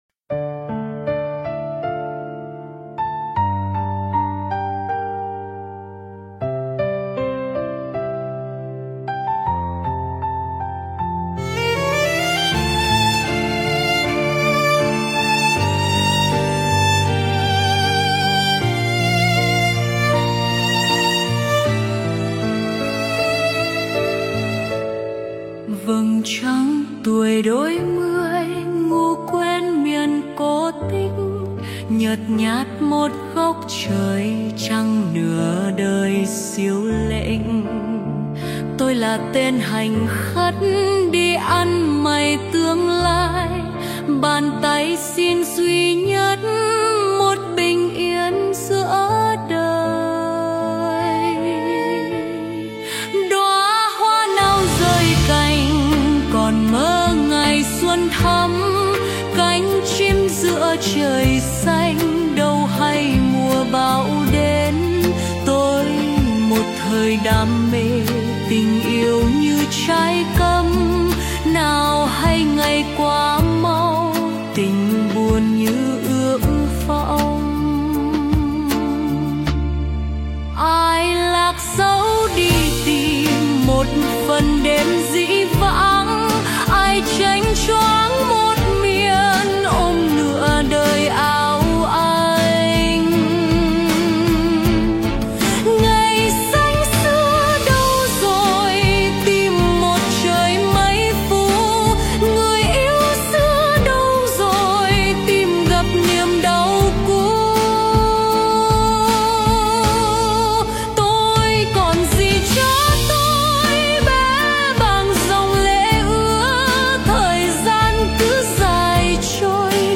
Phổ nhạc: Suno AI